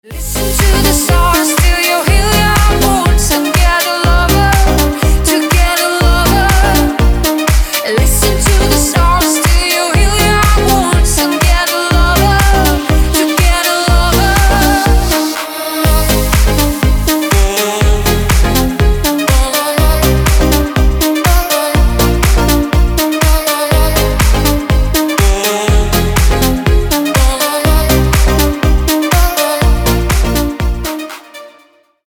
женский вокал
deep house
dance
Electronic
club
vocal